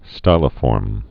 (stīlə-fôrm)